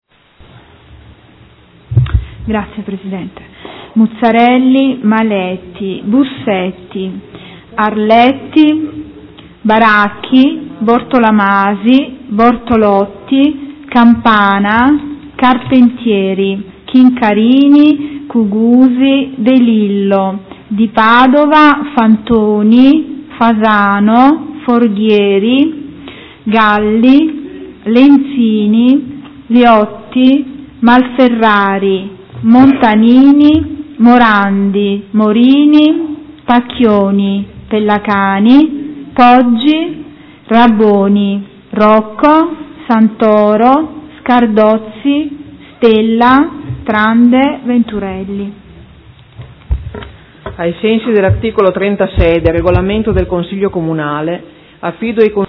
Seduta del 5/5/2016 Appello.
Seduta del 5 maggio 2016